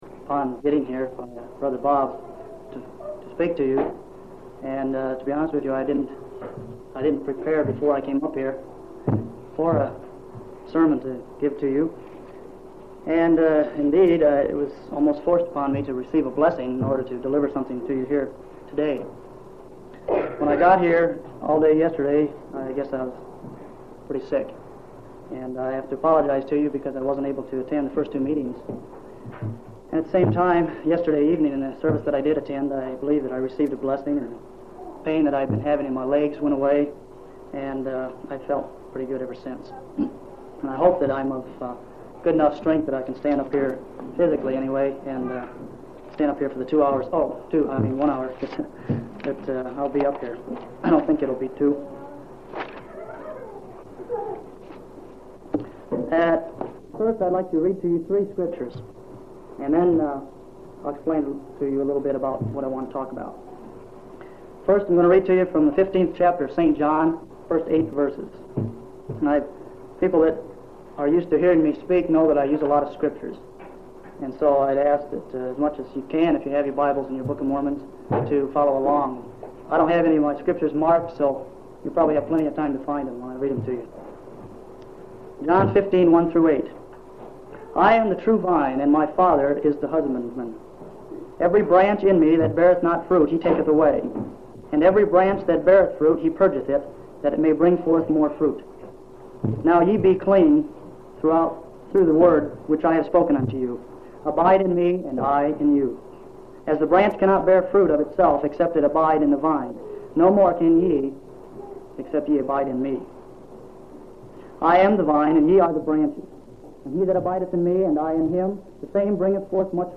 6/18/1975 Location: Colorado Reunion Event